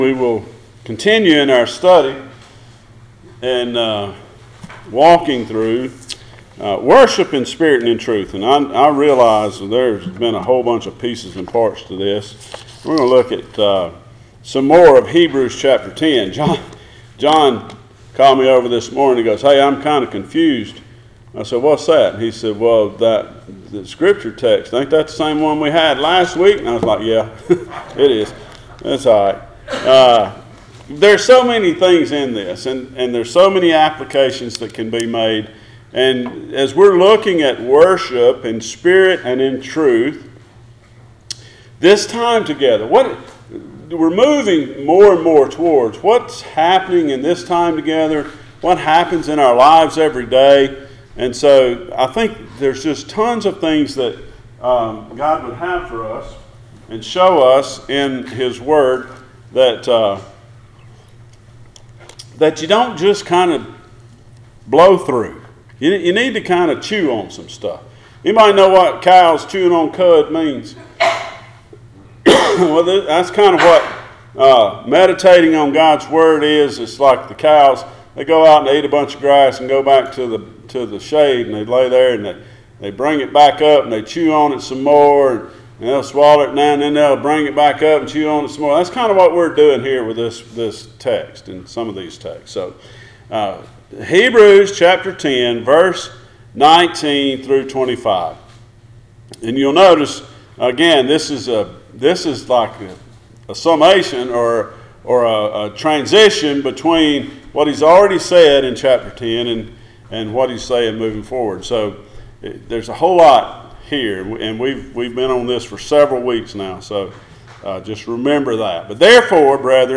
Passage: Hebrews 10:19-25 Service Type: Sunday Morning